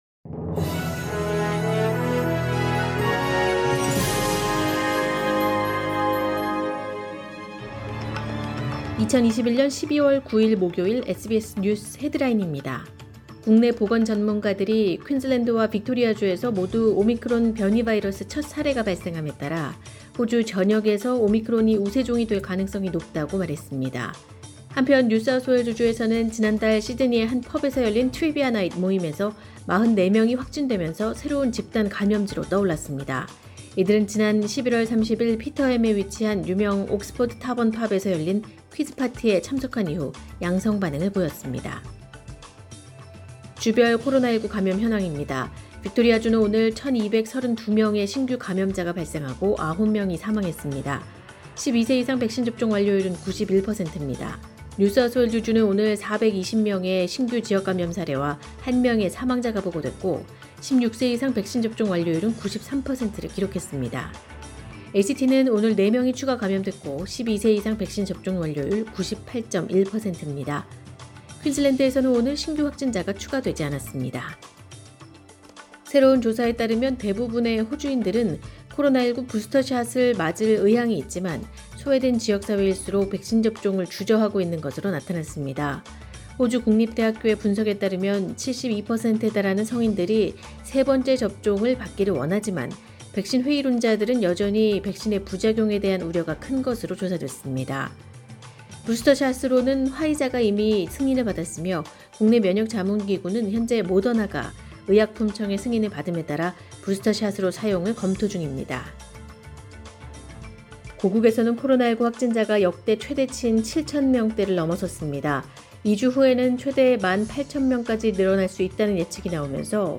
2021년 12월 9일 목요일 오전의 SBS 뉴스 헤드라인입니다.